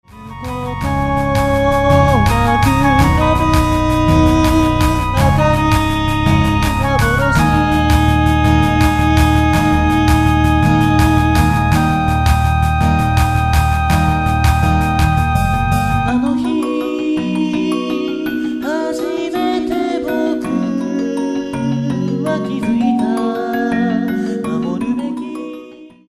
・オリジナルボーカルシングル